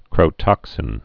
(krōtŏksĭn)